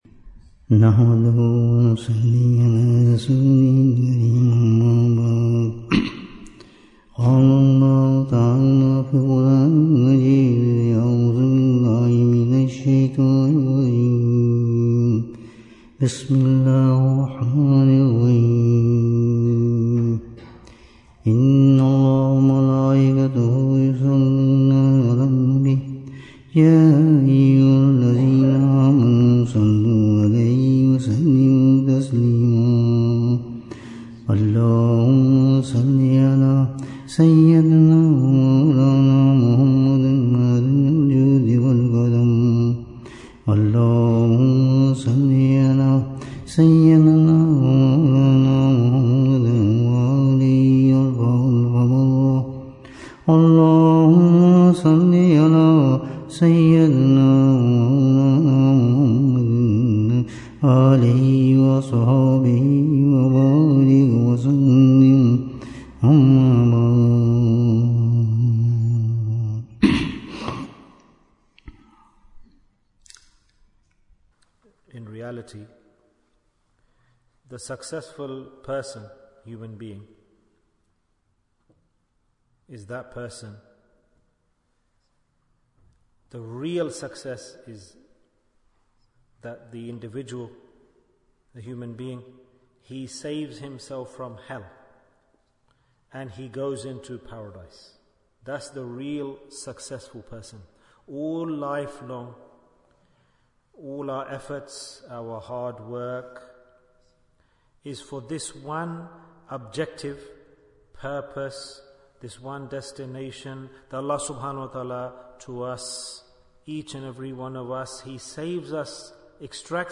Which Ibadah Was Given to Adam (as)? Bayan, 43 minutes16th May, 2024